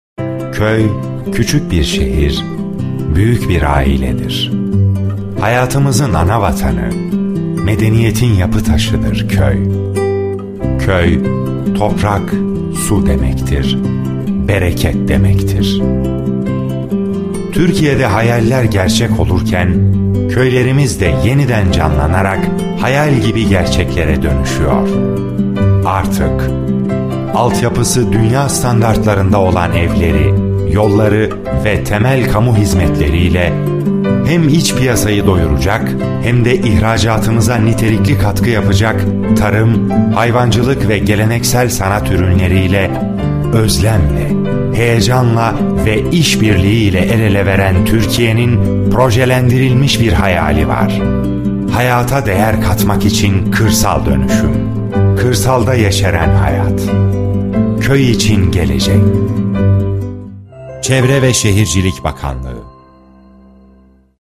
Documentary Voice Over